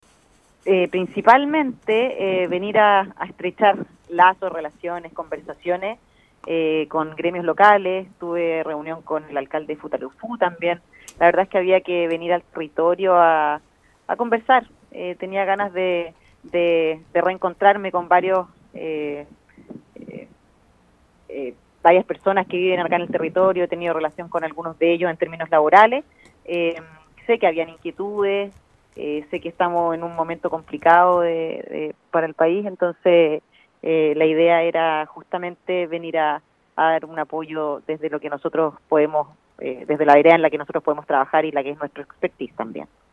En el marco de su visita por la provincia de Palena, la Directora del Servicio Nacional de Turismo en la Región de Los Lagos, Paulina Ros, conversó con Radio Estrella del Mar, oportunidad en que señaló que el objetivo fue poder reunirse con actores ligados a la industria turística.